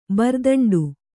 ♪ bardaṇḍu